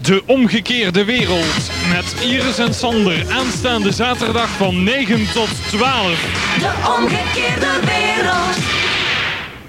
Hier enkele jingles.